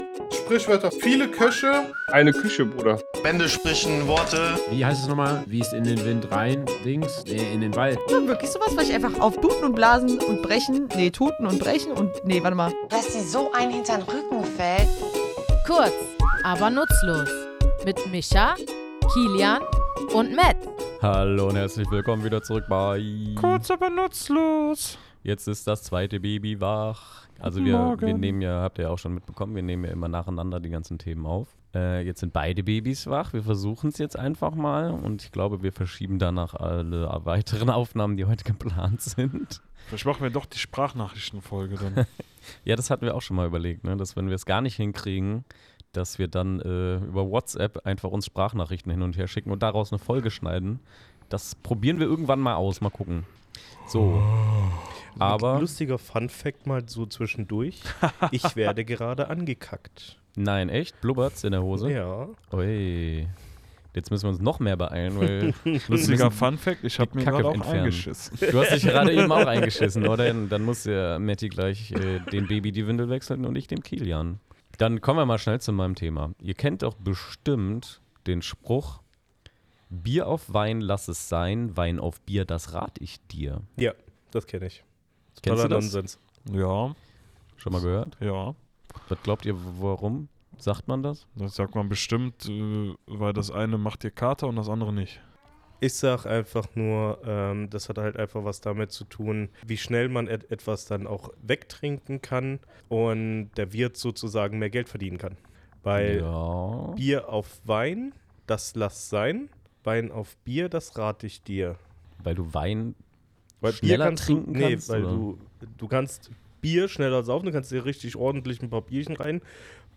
Comedy
Wir, drei tätowierende Sprachliebhaber, gehen in unserem